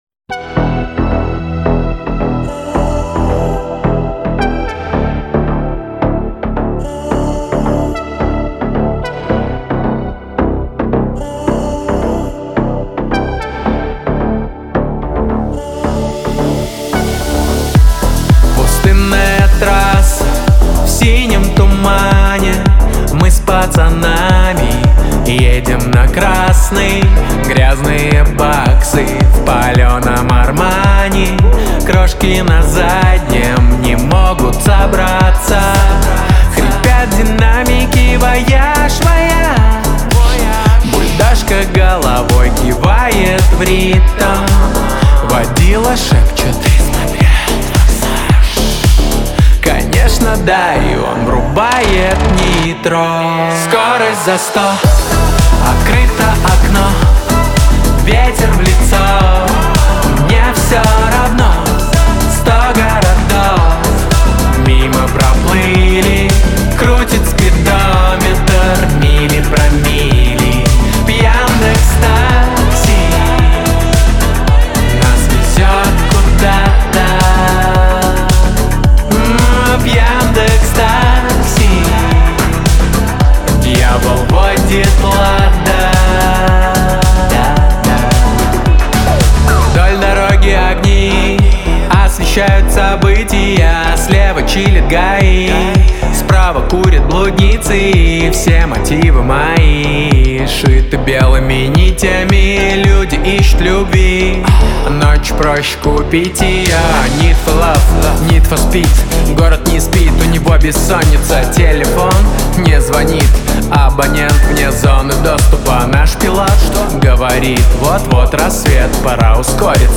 Трек размещён в разделе Поп / 2022 / Казахская музыка.